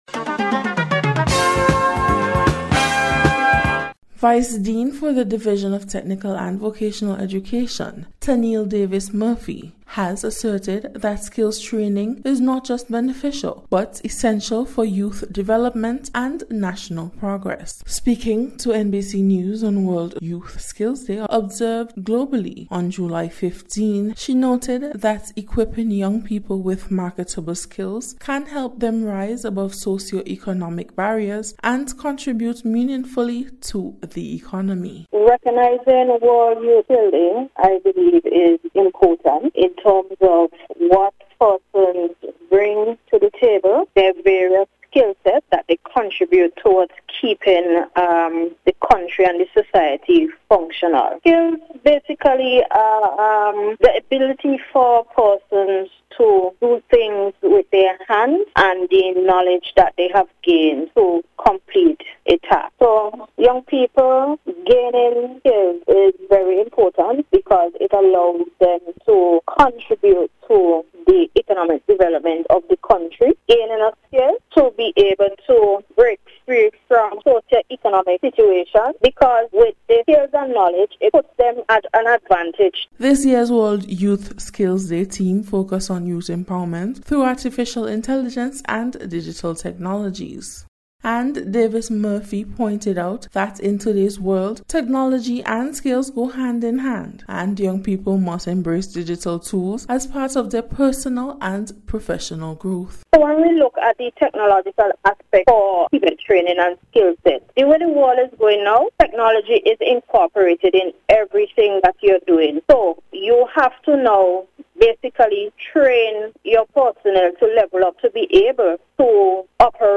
Special Report- Wednesday 16th July,2025